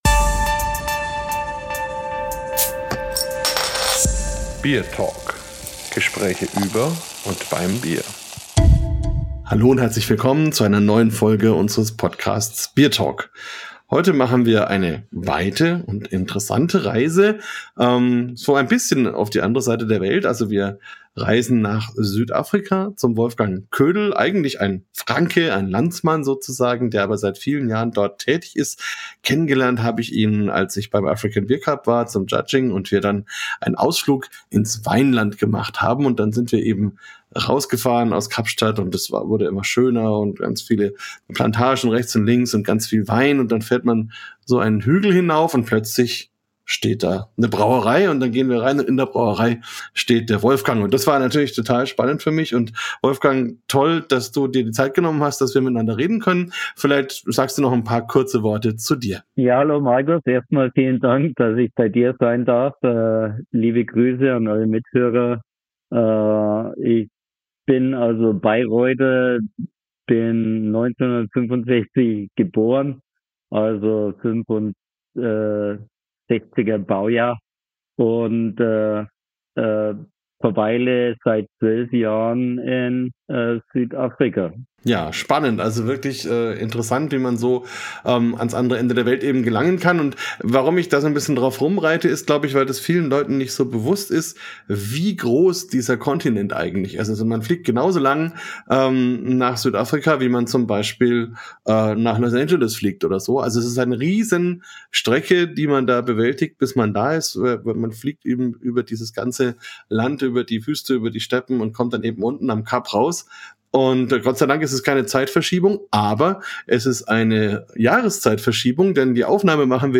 BierTalk Spezial 64 - Interview